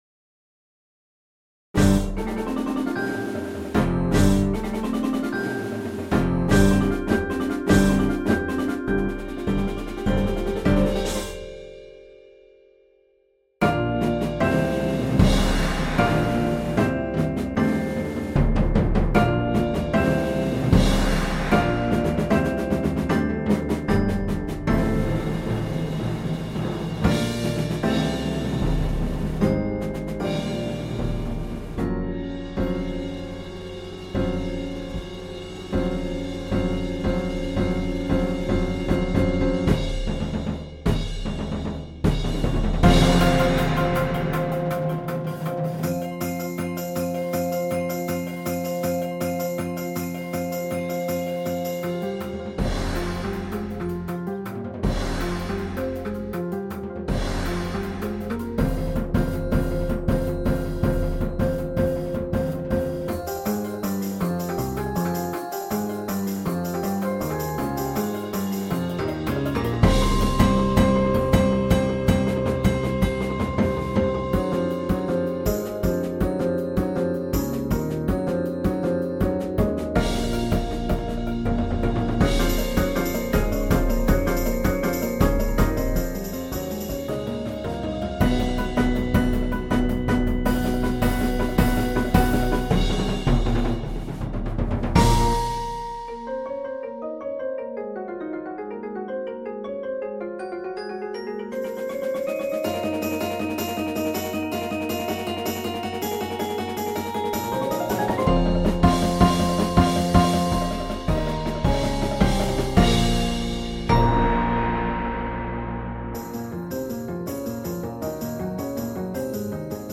• Bells
• 2 Xylophones
• 4 Marimbas
• Guitar
• Synthesizer
• Bass (or Mallet Kat) Timpani
• Drum Set
• 4 Aux. Percussion